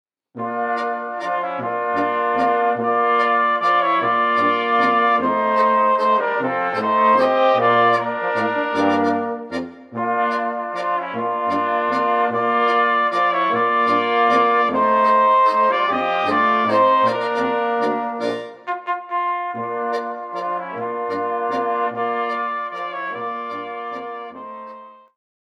Volksmusik Tanzlmusik